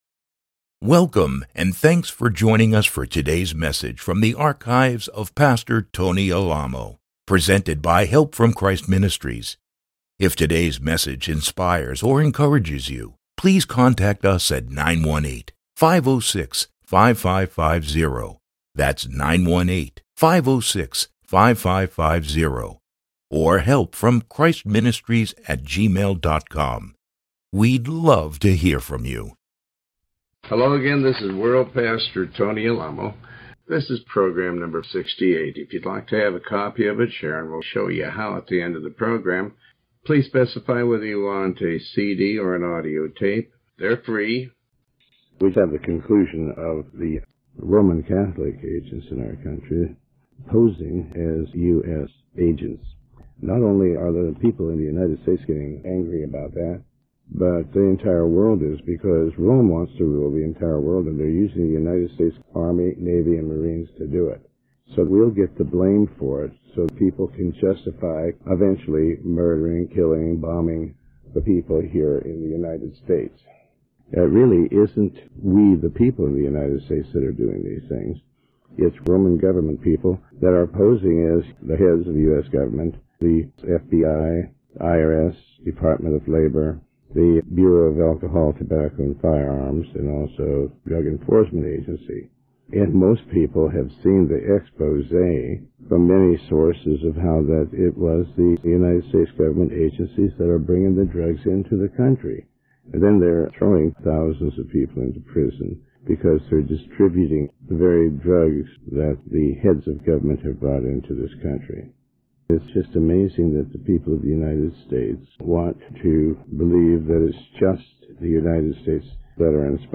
Sermon 68